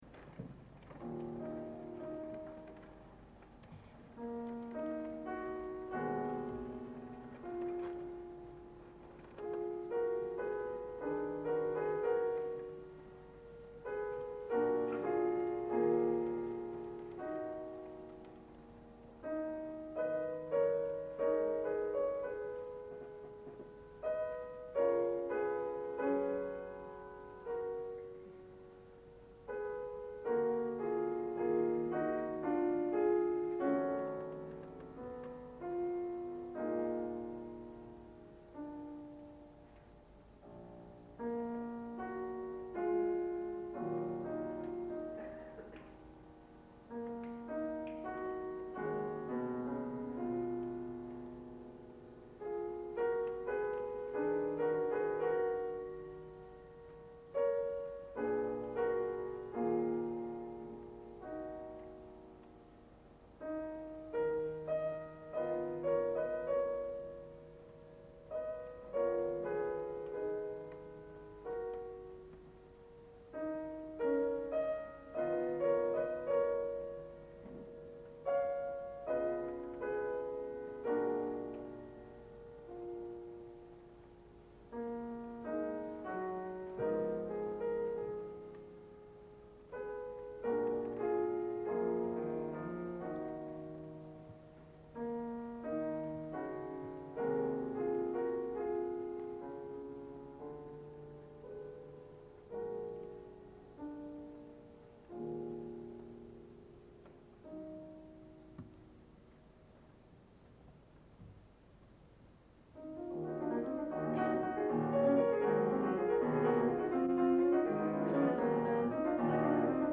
Canción y danza nº 6 (grabación en directo). Concierto de entrega de premios de la Muestra de Jóvenes Intérpretes "Ciudad de Málaga 1996", Teatro Cervantes de Málaga, 1996